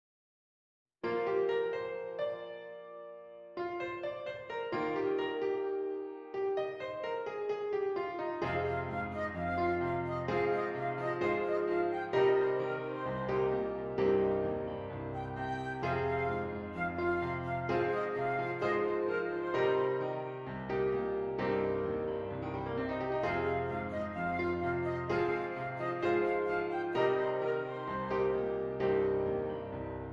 Flute Solo with Piano Accompaniment
E FLat Major
Moderate